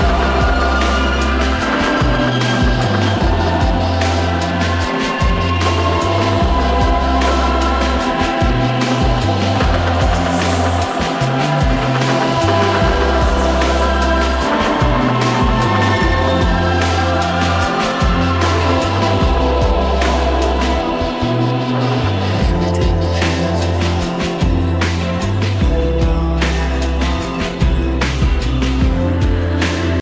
e talvez marcante na pop/rock de 2025